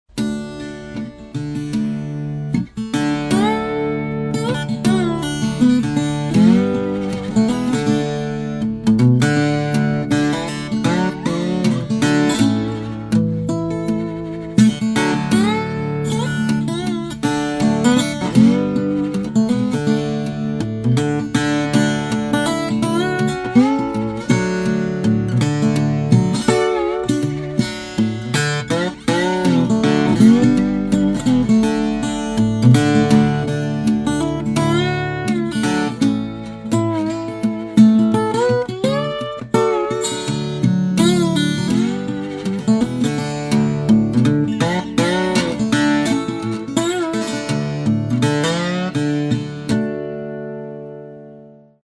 Acoustic Slide Guitars
The difference in sound with the floating bridge version is that the attack is slightly rounder but the sustain following the note is not as loud.
Blackwood top floating bridge audio